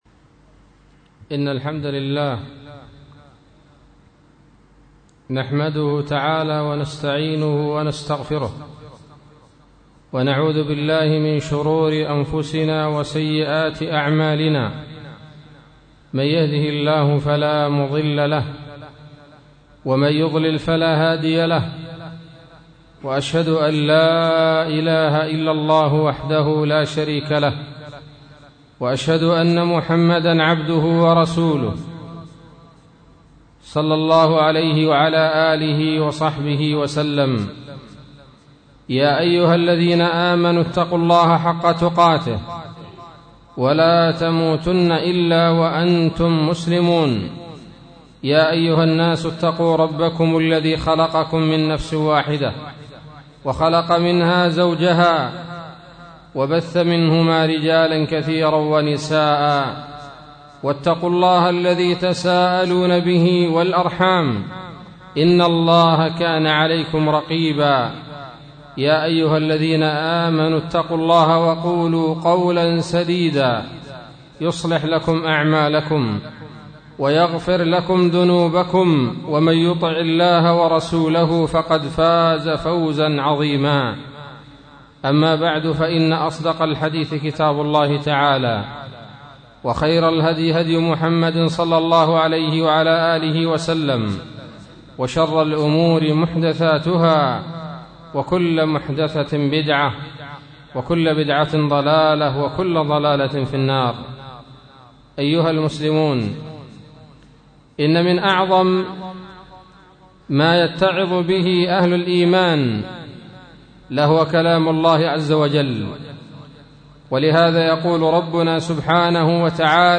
خطبة بعنوان : ((تفسير سورة القارعة)) 14 ربيع الأول 1437 هـ